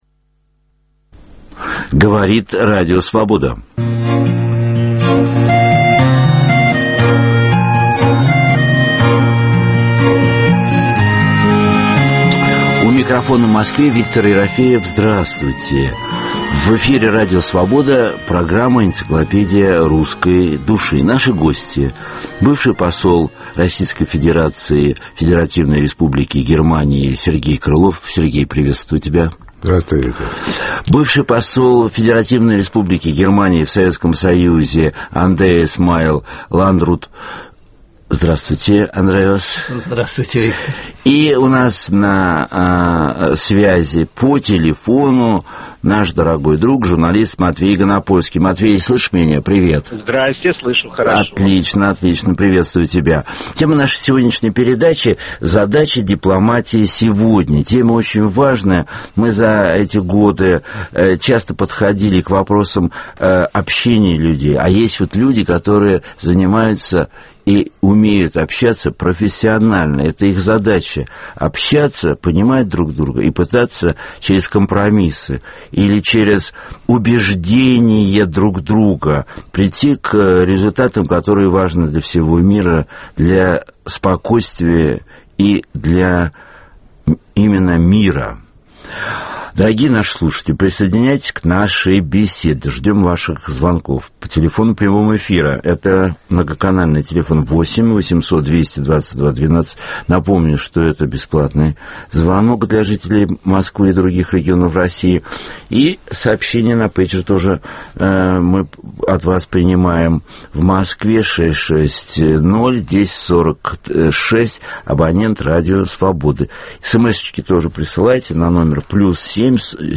Живой опыт самопознания в прямом эфире с участием слушателей, который ведет писатель Виктор Ерофеев. Это попытка определить наши главные ценности, понять, кто мы такие, о чем мы спорим, как ищем и находим самих себя.